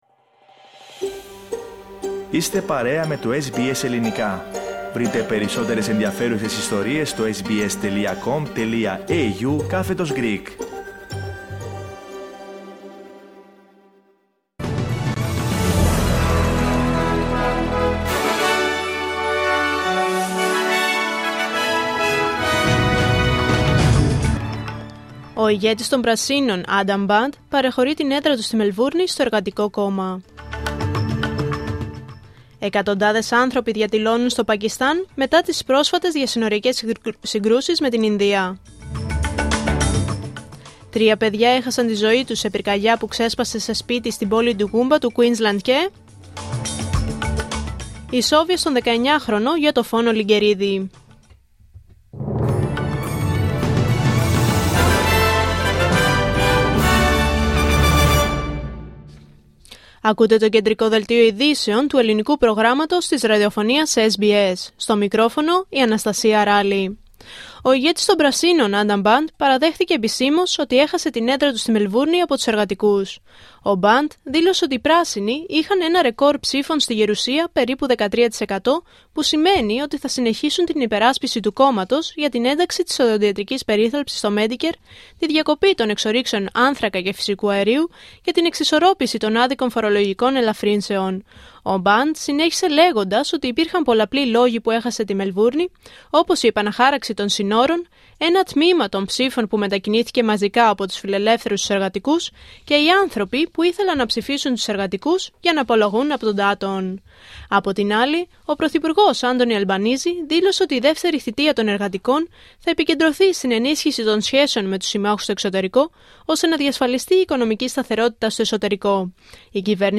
Δελτίο Ειδήσεων Πέμπτη 08 Μαΐου 2025